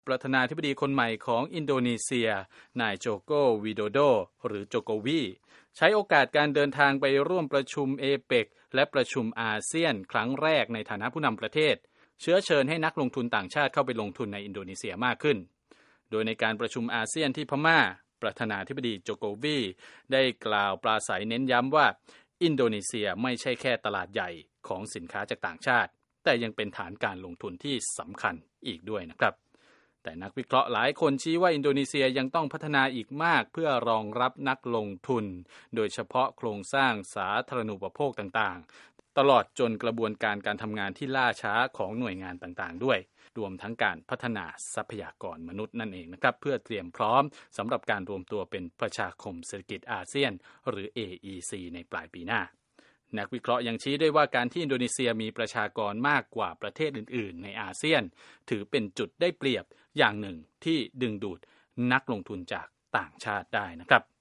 ปธน. Joko Widodo กล่าวปราศรัยที่การประชุมอาเซียนว่า อินโดนีเซียไม่ใช่แค่เป็นตลาดใหญ่ของสินค้าต่างชาติ แต่ยังเป็นฐานการลงทุนที่สำคัญ